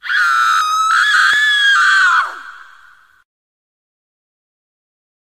scream.mp3